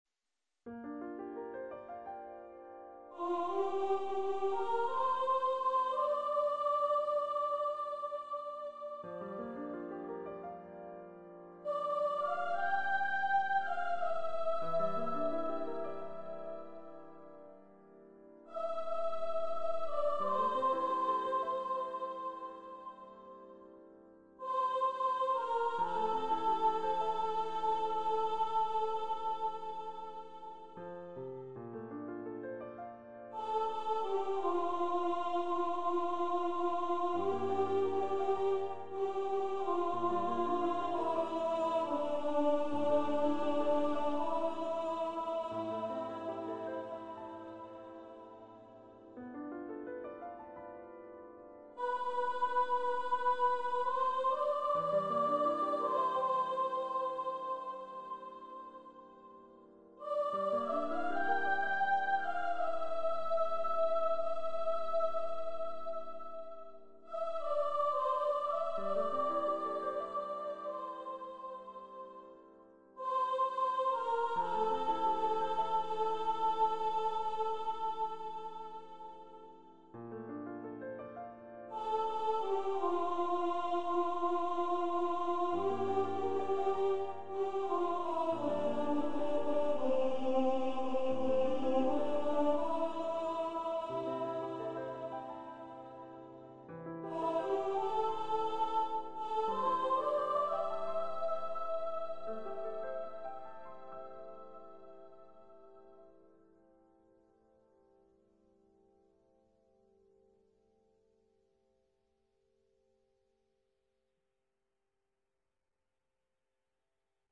Voice, Piano
Composer's Demo